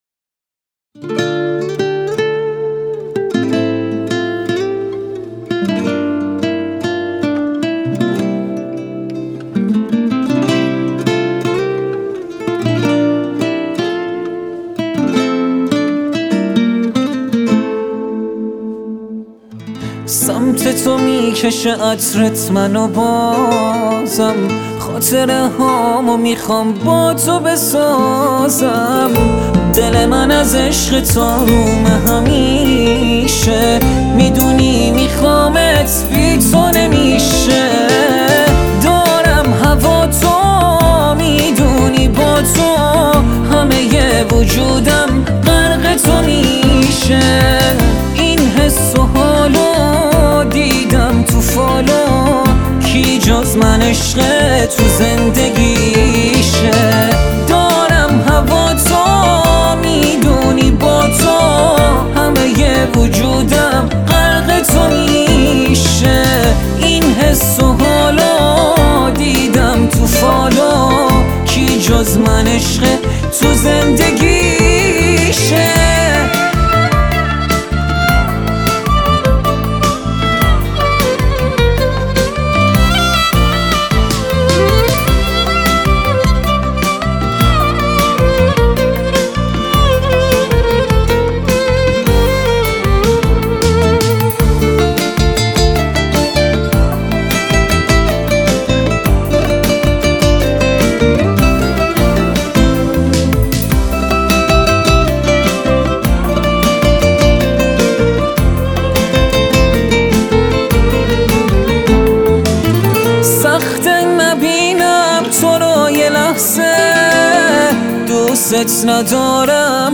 گیتار
ویلون